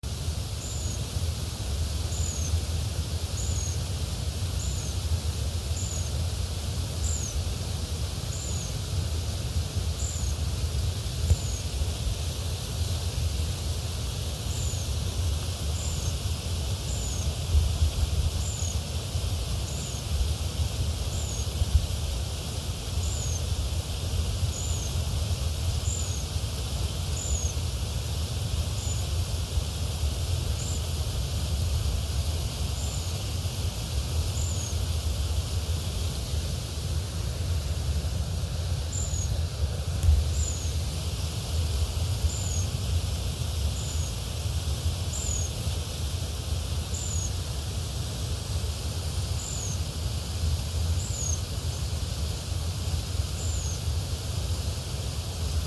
Ratona Ceja Blanca (Troglodytes solstitialis)
Nombre en inglés: Mountain Wren
Localidad o área protegida: Parque Provincial Potrero de Yala
Localización detallada: Río Yala a la altura de la toma de agua
Condición: Silvestre
Certeza: Fotografiada, Vocalización Grabada
Ratona-ceja-blanca_1.mp3